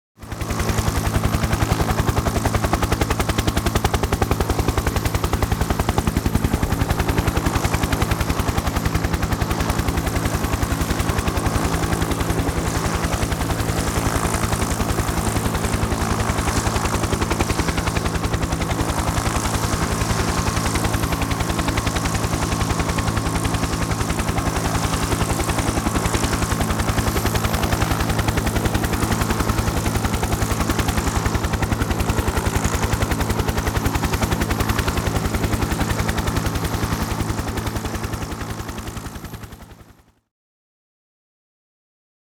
直升机 后面探照灯追玩家用.wav